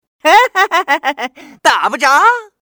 hahaha2.mp3